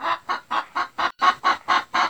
Added chicken sounds to the chickens.